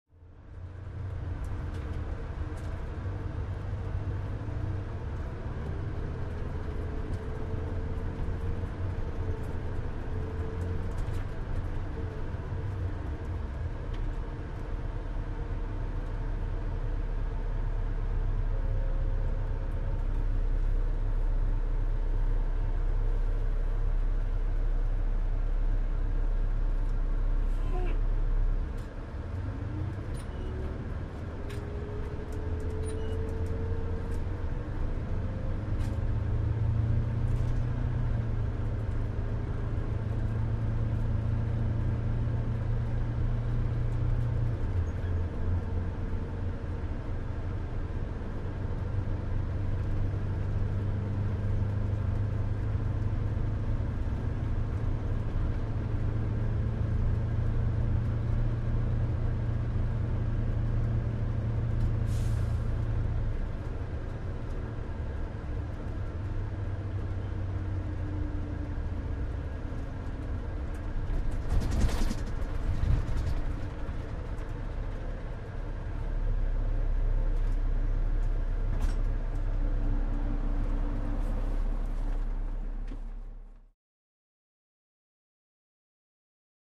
Interior, Bus | Sneak On The Lot
Bus On Board With Start And Stop